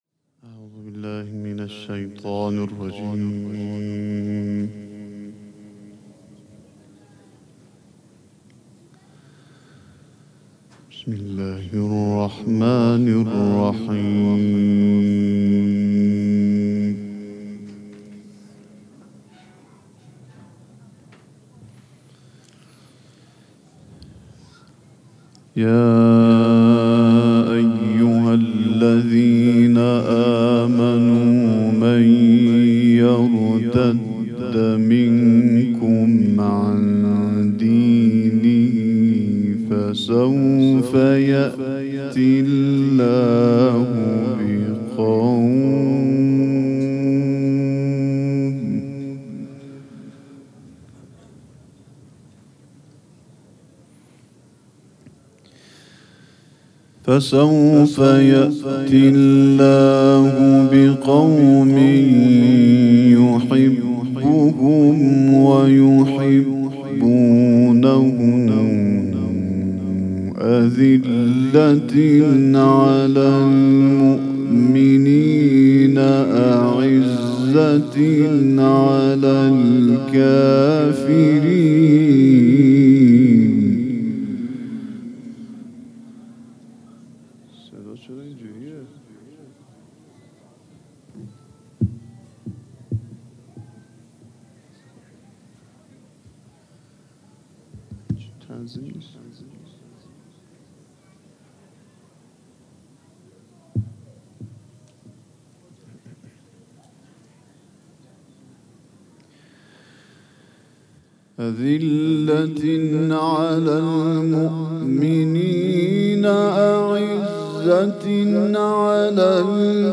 قرائت قرآن کریم
حسینیه ریحانة‌الحسین (سلام‌الله‌علیها)
قرائت قرآن
جشن عید غدیرخم